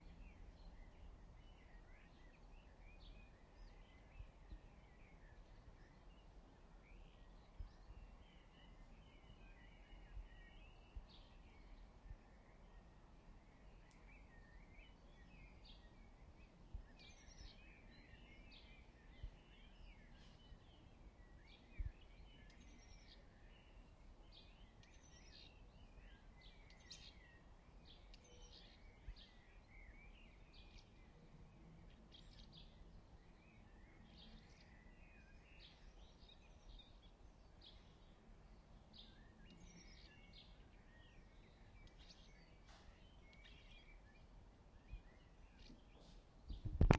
清晨4点半的鸟叫
描述：清晨4点半的鸟叫。注意听！有只猫过来凑热闹哦！
标签： 清晨 鸟叫 猫叫 小鸟
声道立体声